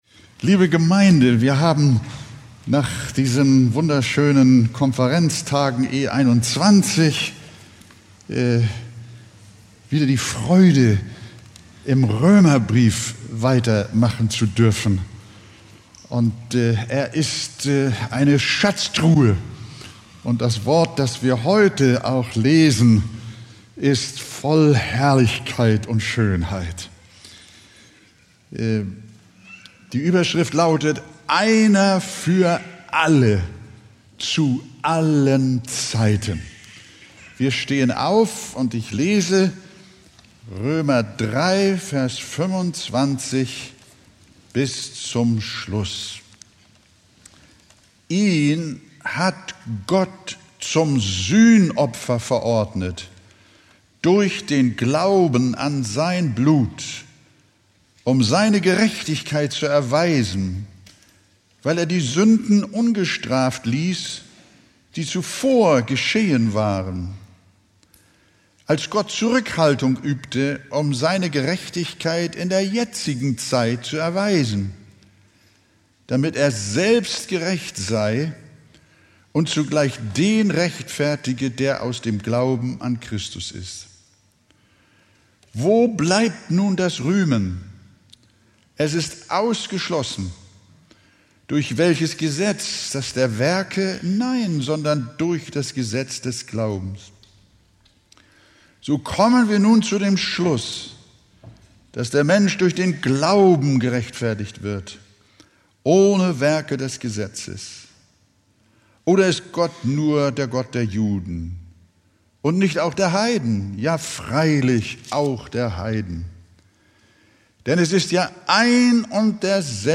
Predigttext: Römer 3,25-31